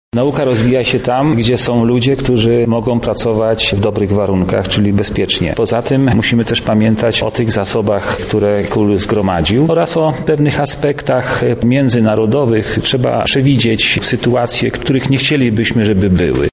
W uroczystości brał też udział Andrzej Stanisławek, sekretarz stanu w Ministerstwie Nauki i Szkolnictwa Wyższego, który podkreśla znaczenie tego przedsięwzięcia: